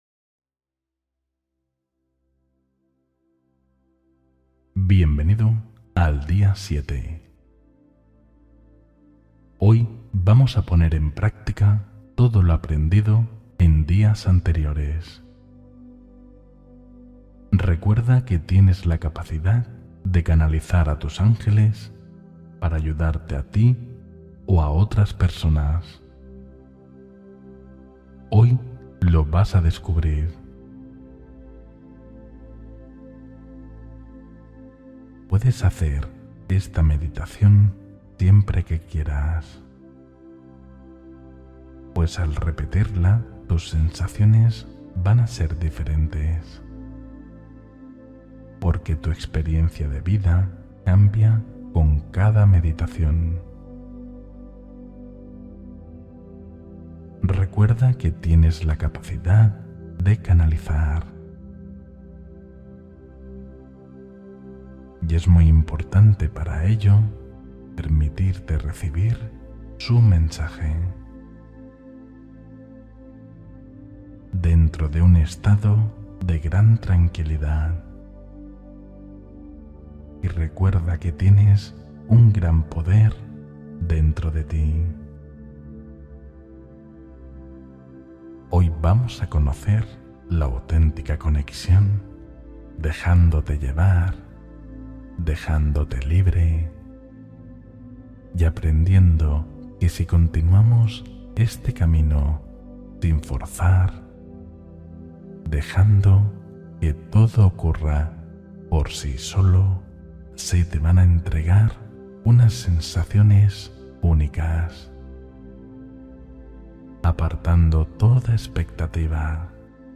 Conecta con tu espiritualidad más profunda con esta meditación guiada – Día 7